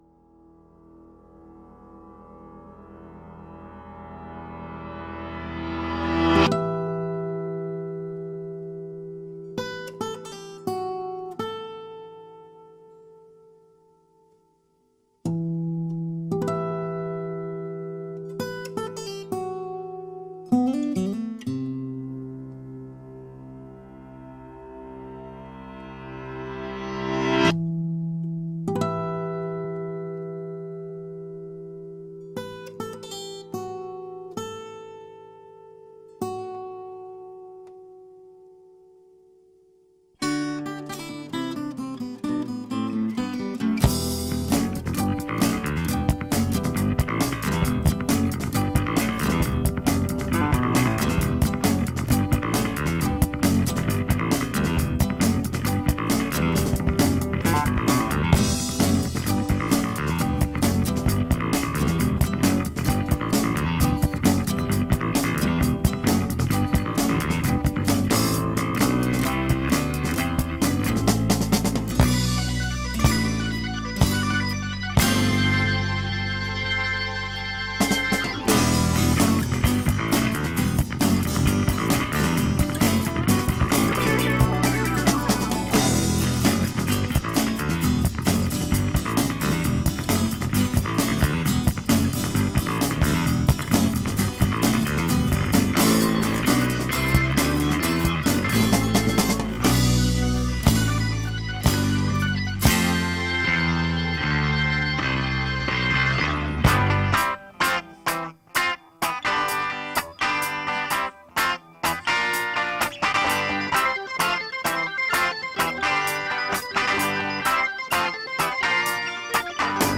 Special Guest on Flute